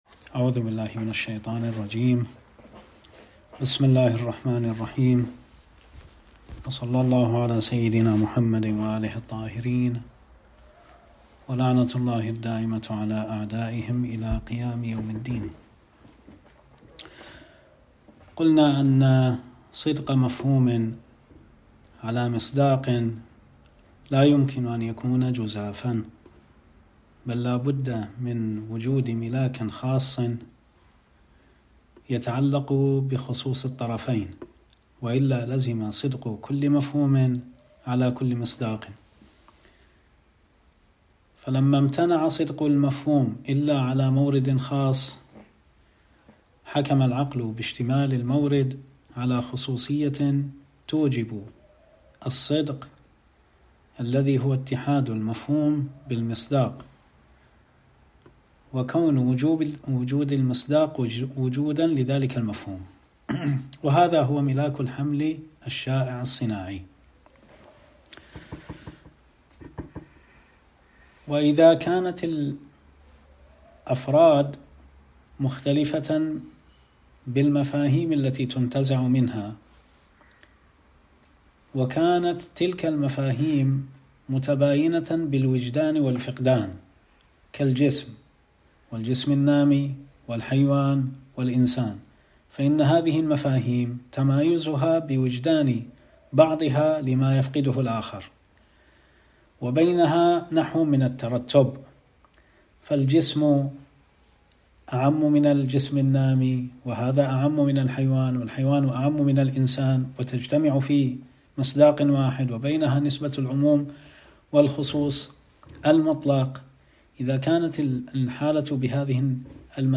صوت الدرس